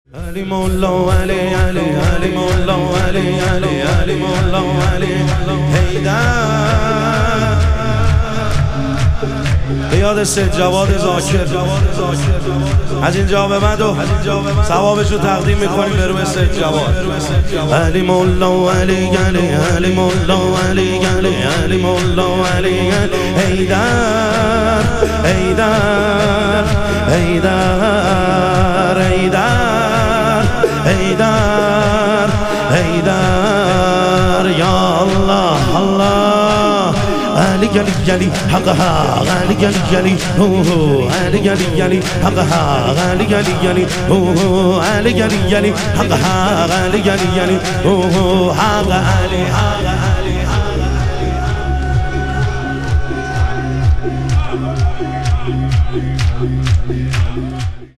شهادت حضرت ام البنین علیها سلام - شور